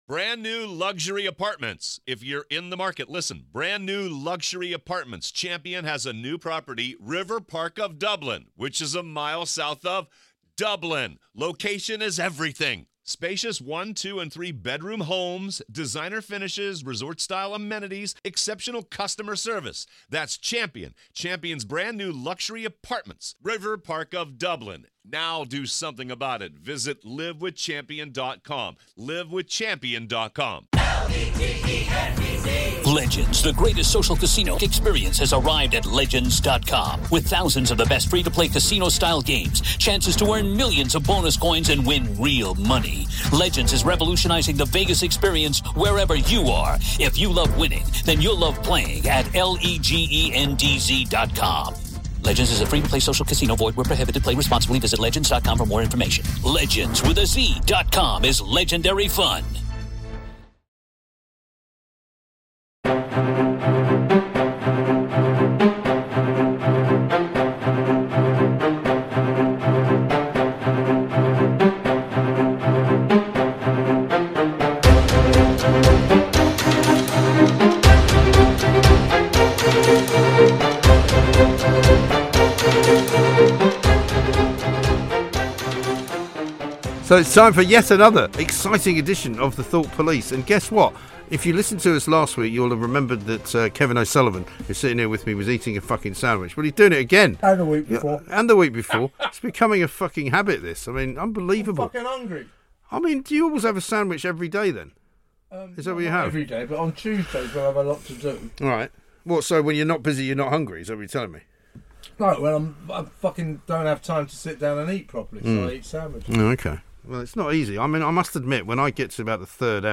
The lads are back with another rage filled deep dive into the past weeks top headlines. From the latest Oscar acclaimed films no one made the effort to see, vaccination mandate madness, the cancellation attempt of Joe Rogan and much much more, so tune in!